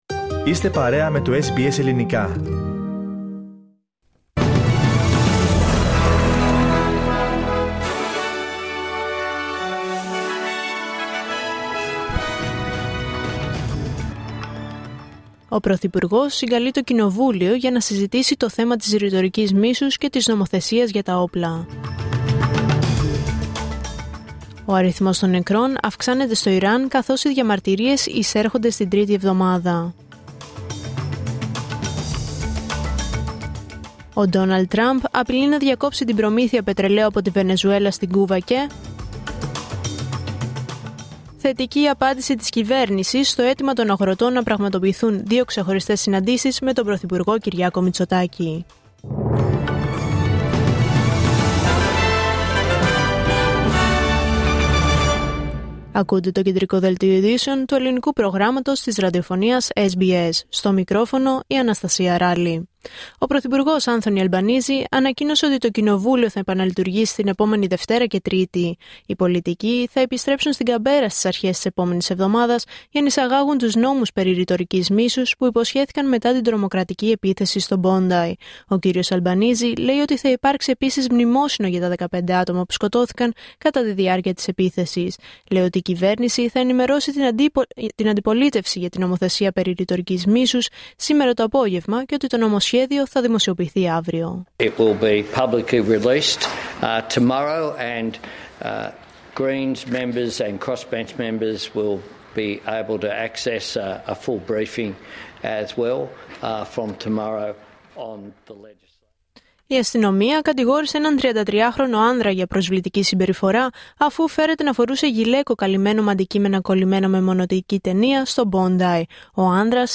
Δελτίο Ειδήσεων Δευτέρα 12 Ιανουαρίου 2026